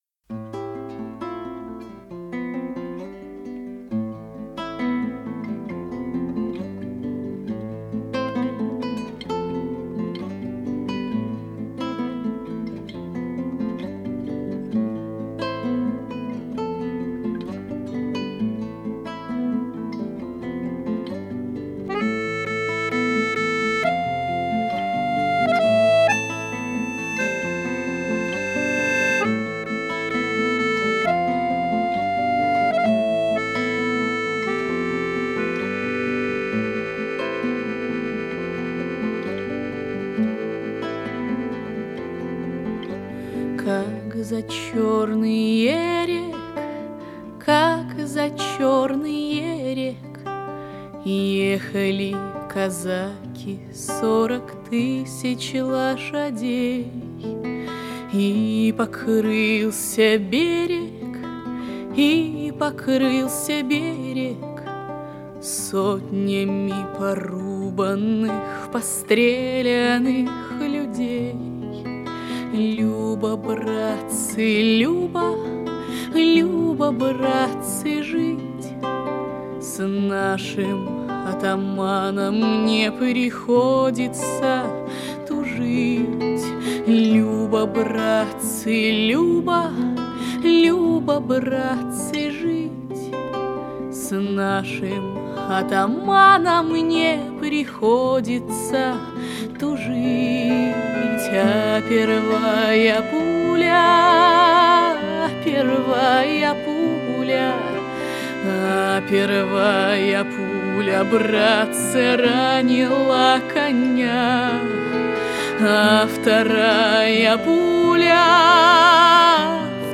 Næste ting er en næsten skræmmende udgave af en gammel kosaksang.
Udgaven nedenunder er imidlertid en nyere indspilning.